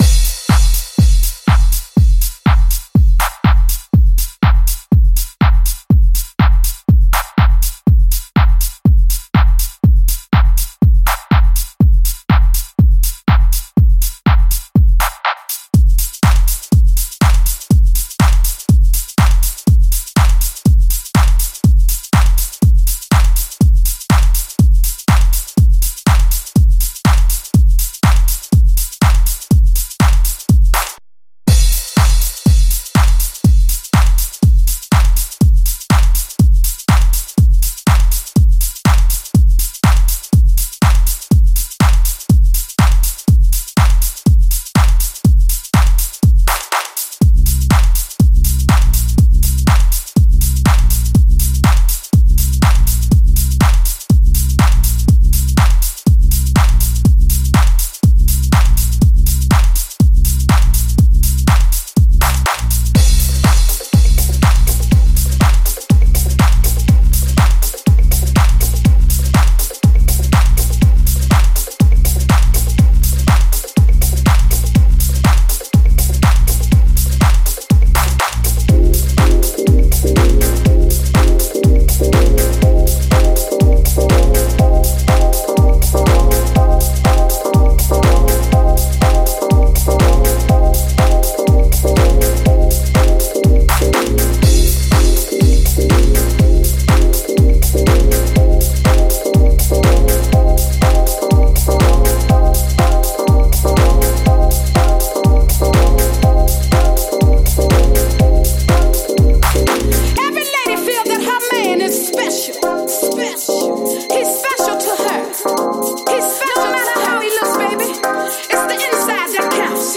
pure house gem